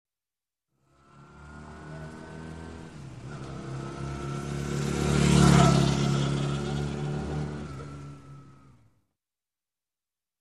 Мотоцикл 250 куб.см проезжает мимо со средней скоростью
Тут вы можете прослушать онлайн и скачать бесплатно аудио запись из категории «Мотоциклы».